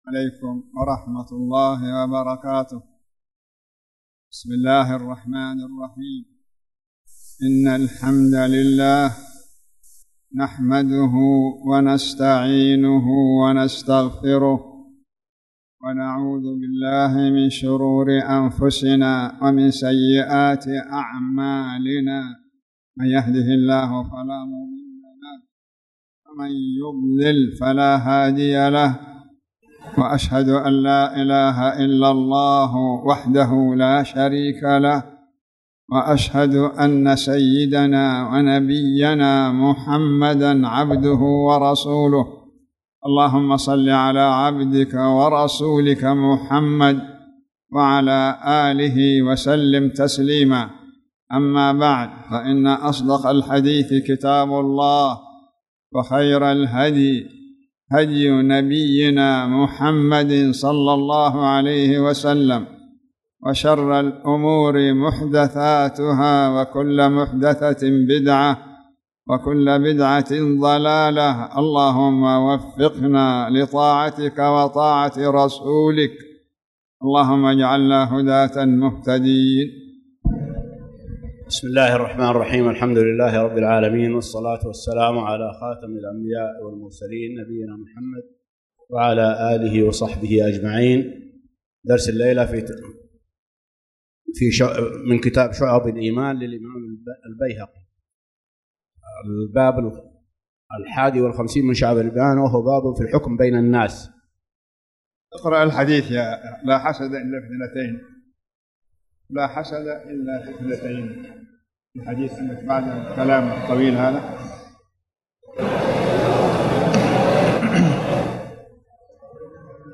تاريخ النشر ١٢ ربيع الثاني ١٤٣٨ هـ المكان: المسجد الحرام الشيخ